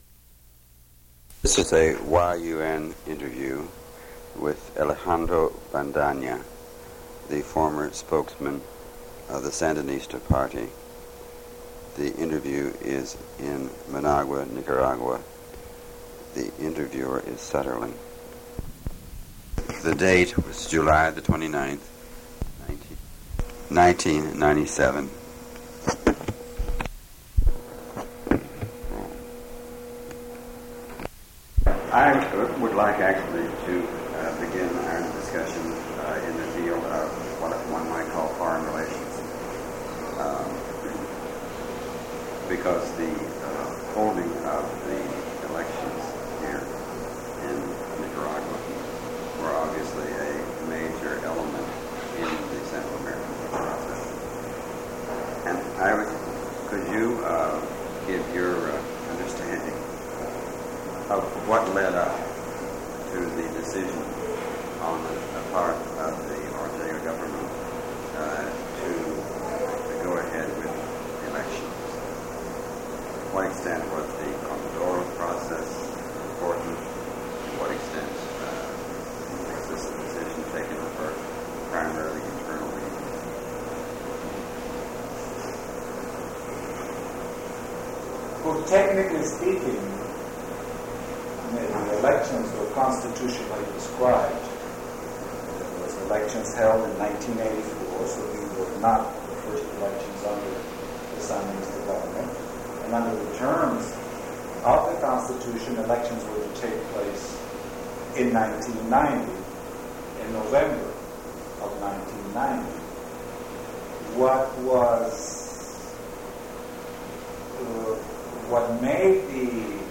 Interview with Alejandro Bendaña /